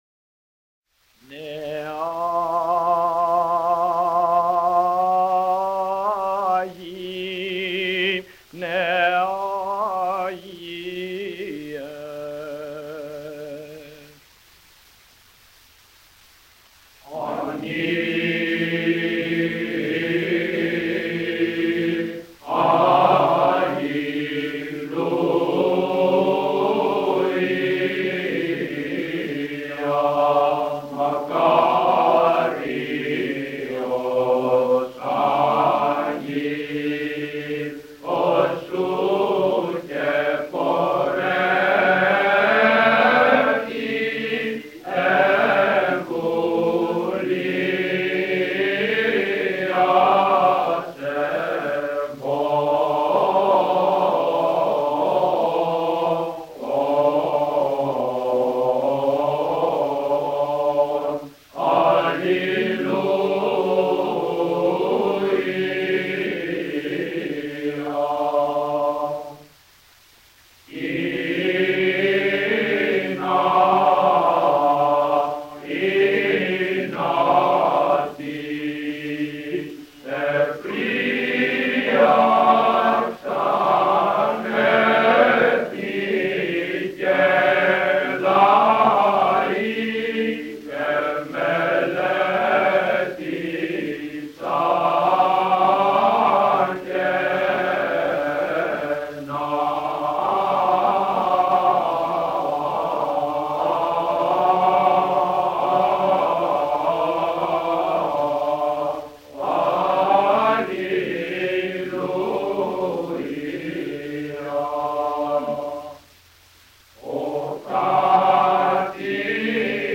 Mode IV plagal